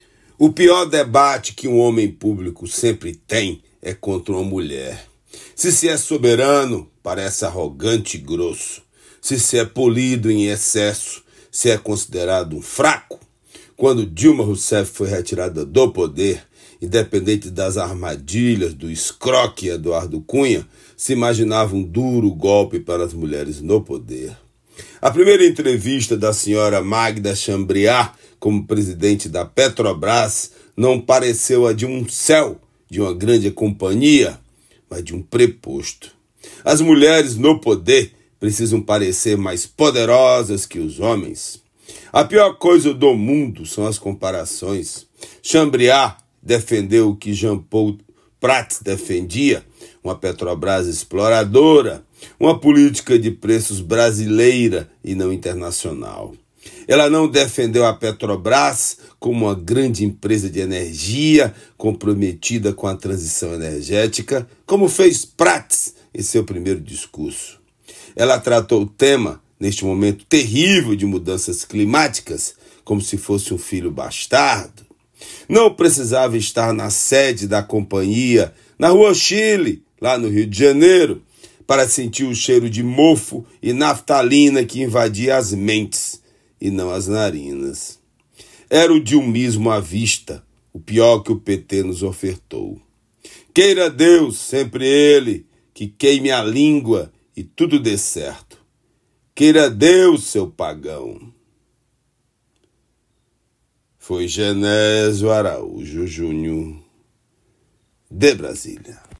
Comentário desta terça-feira
direto de Brasília.